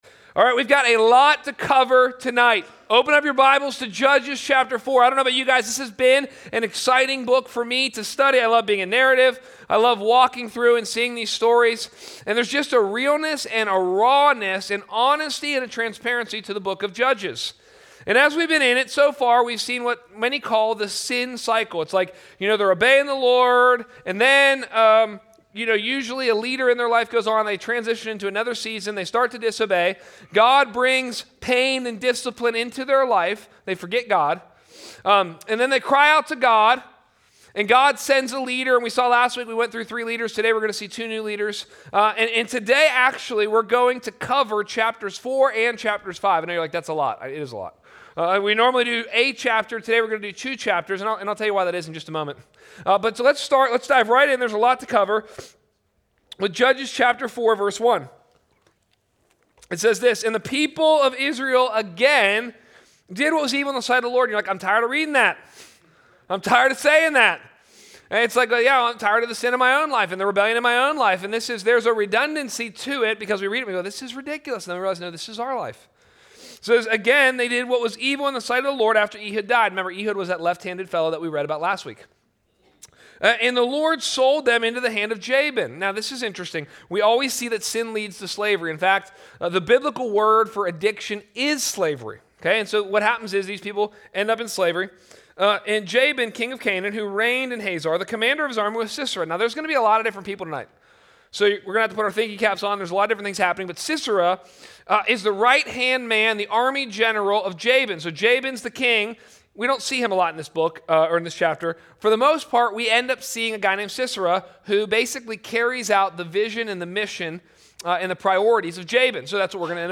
A message from the series "The Book of Judges."